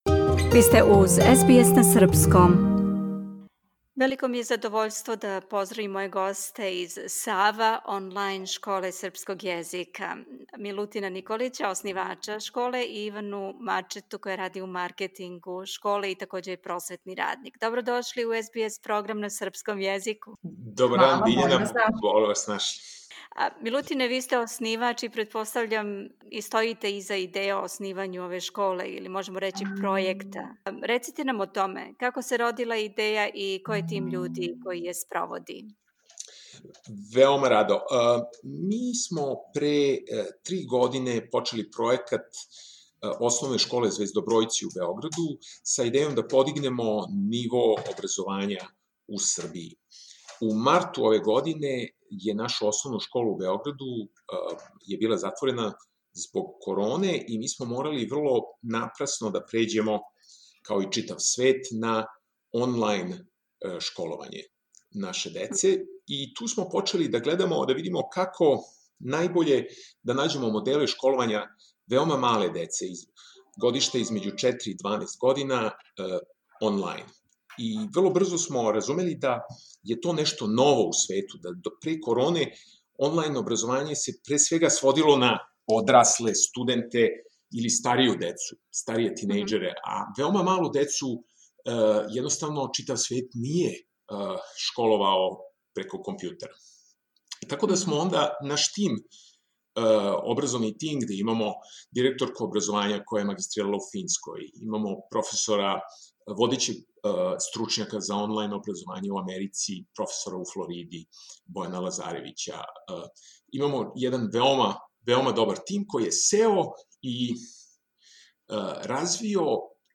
sava_projekat_interview_web.mp3